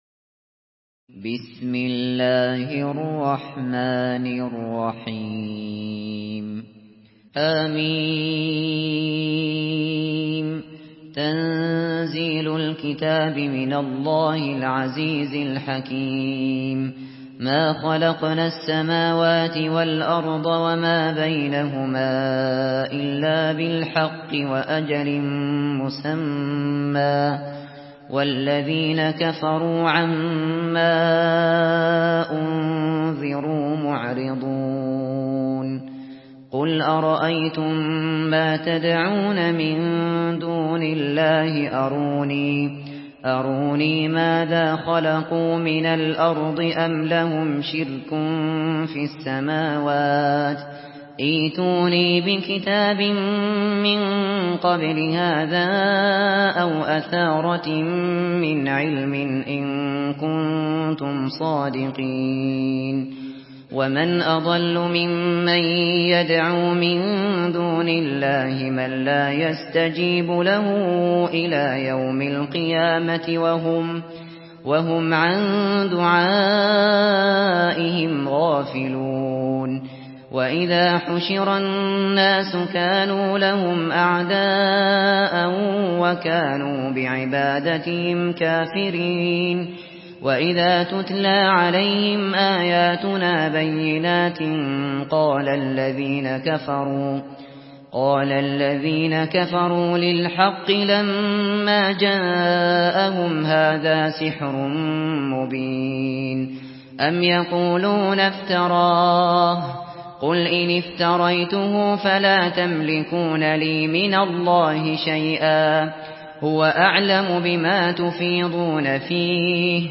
Surah Ahkaf MP3 by Abu Bakr Al Shatri in Hafs An Asim narration.
Murattal Hafs An Asim